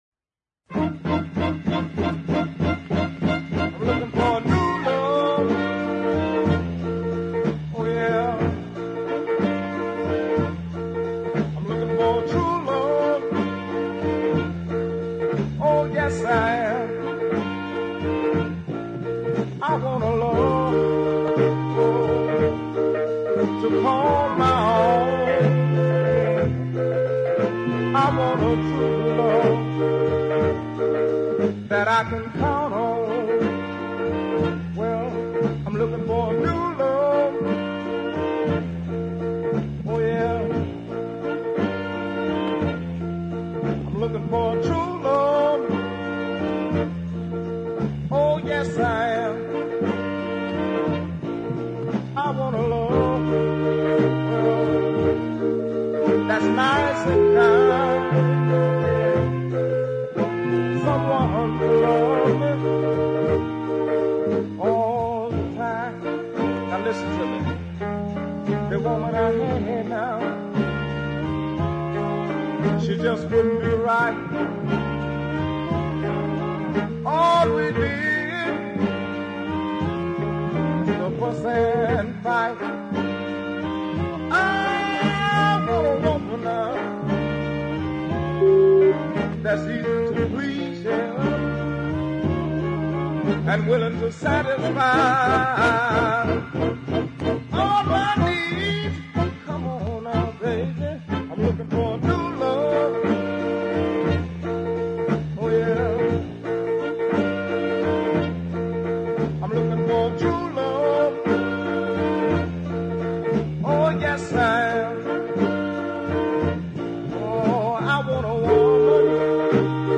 the ballad
amid a plodding rhythm section and good horn support